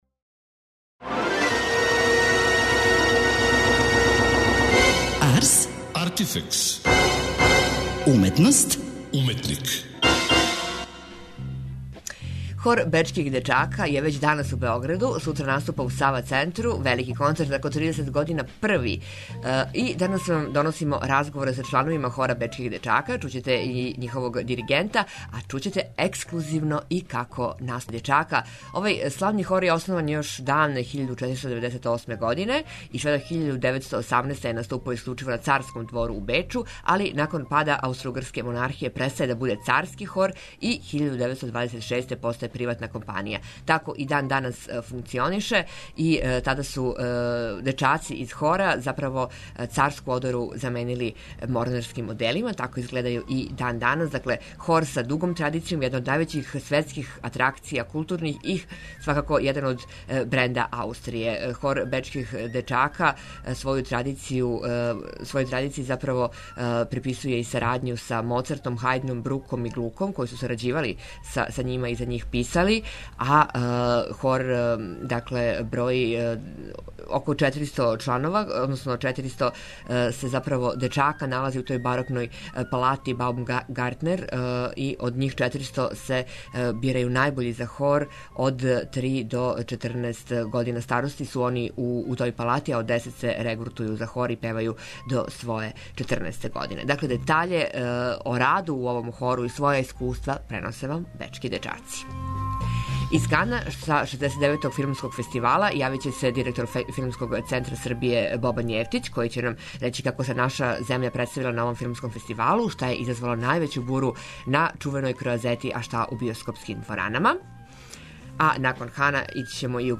У данашњој емисији чланови хора говоре нам о својим искуствима, а ексклузивно ћемо чути уживо извођење једне њихове нумере.